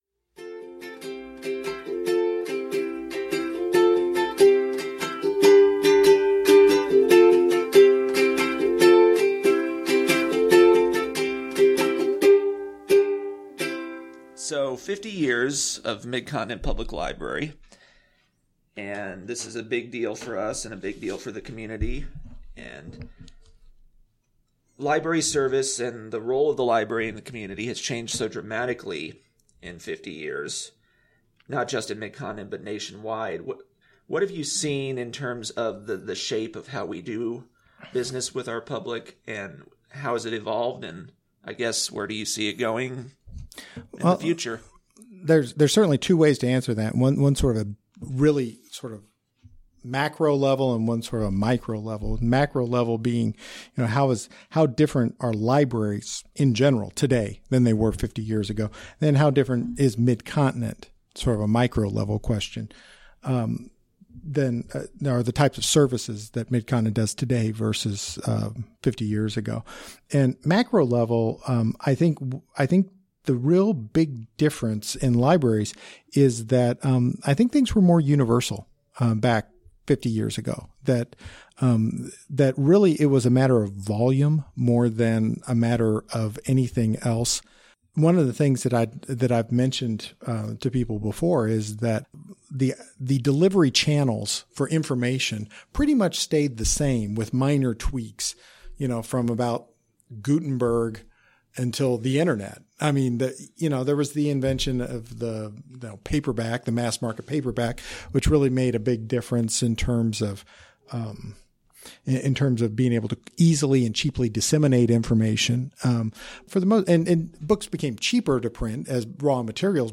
For its 50th anniversary, a series of interviews were recorded detailing the story of Mid-Continent Public Library.